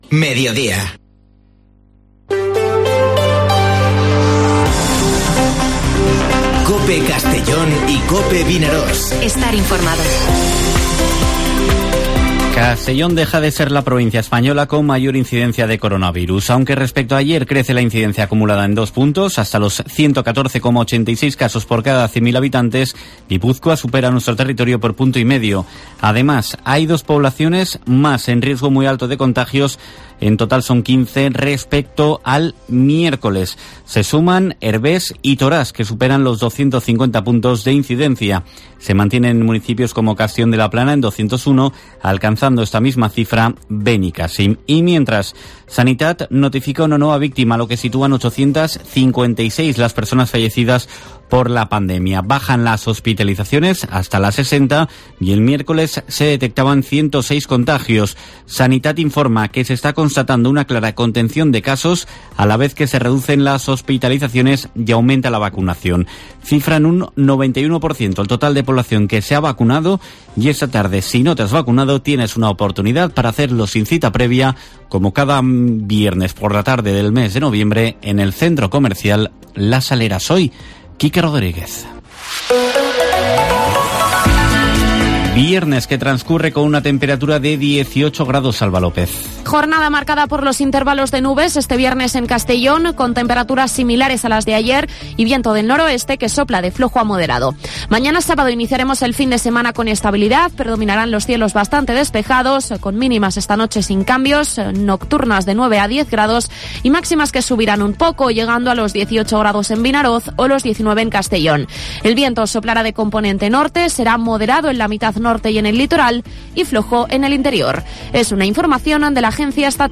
Informativo Mediodía COPE en la provincia de Castellón (05/11/2021)